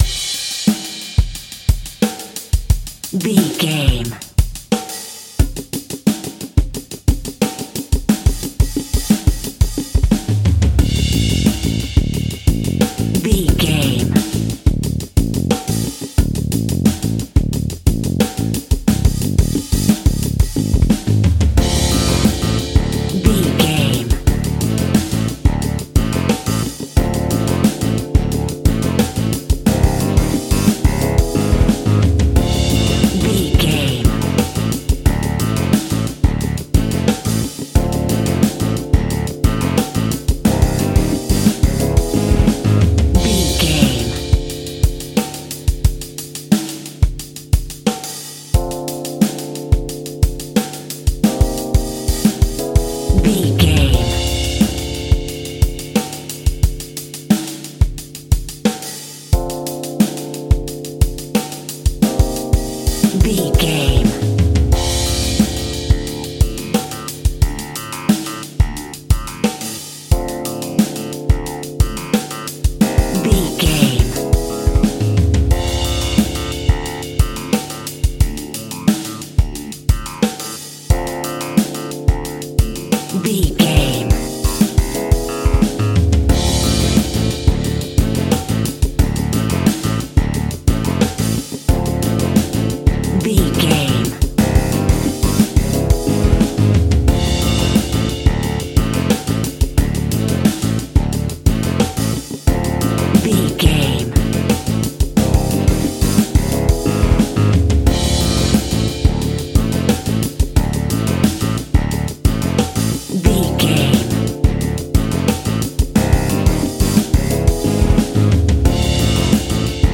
Aeolian/Minor
world beat
tropical
salsa
drums
bass guitar
electric guitar
piano
hammond organ
percussion